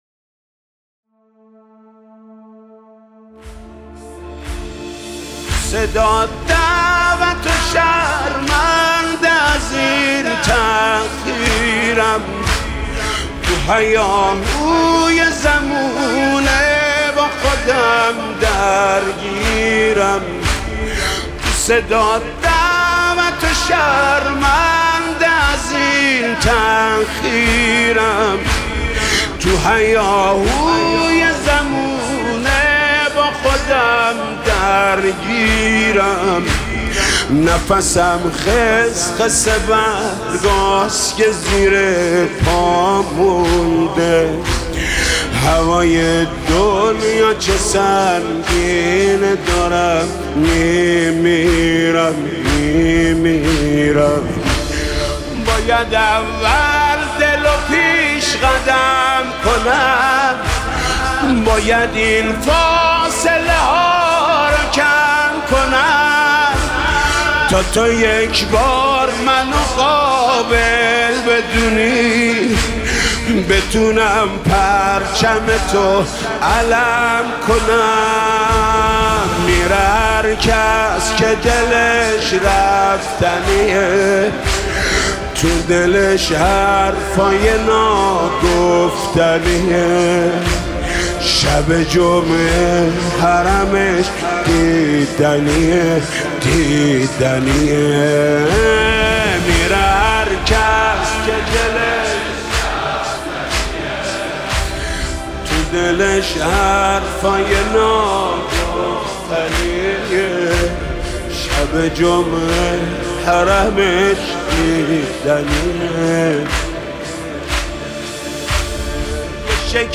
مداح اهل بیت